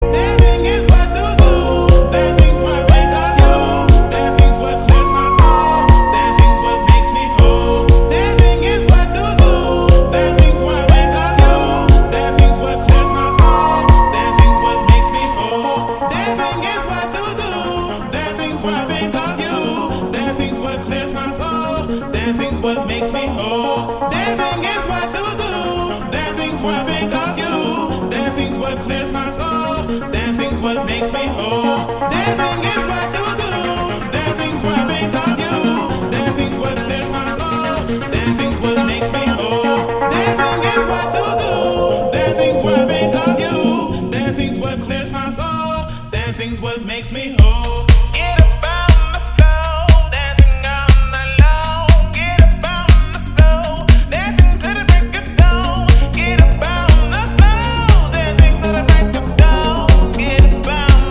• Качество: 320, Stereo
ритмичные
мужской вокал
громкие
deep house
EDM